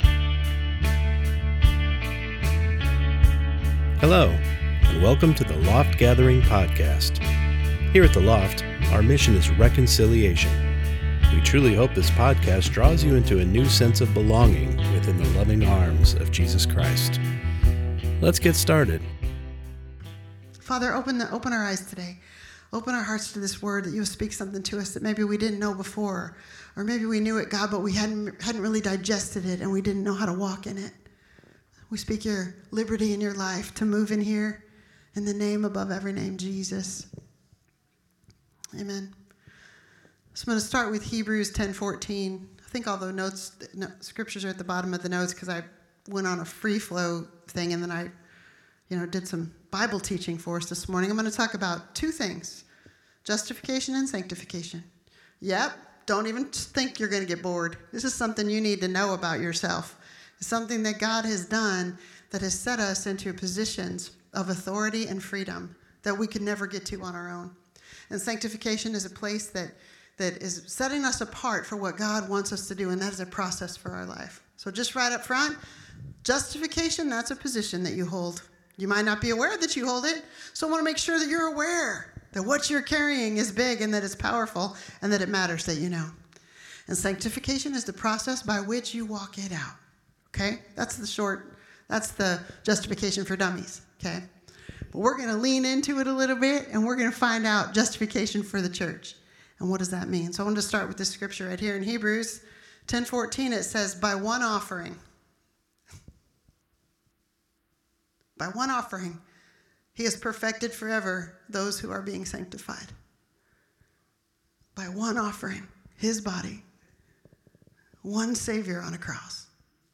Sunday Morning Service Service